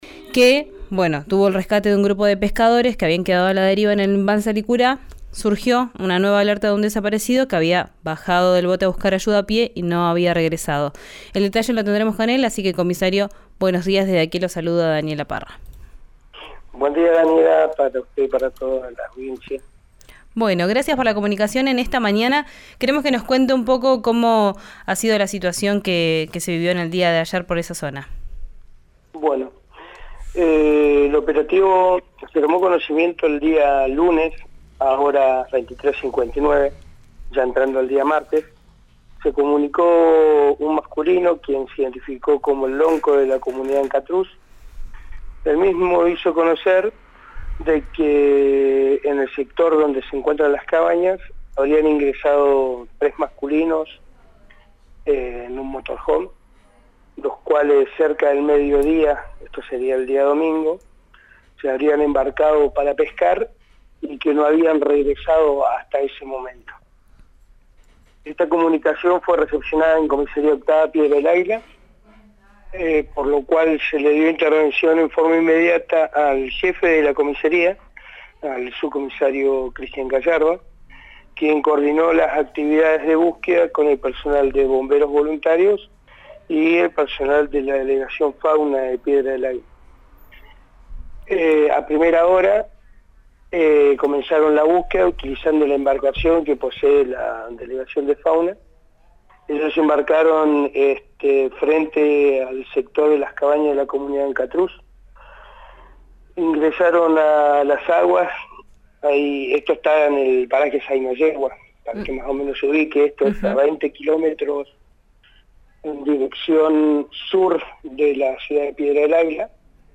en el aire de »Vos en verano» por RÍO NEGRO RADIO.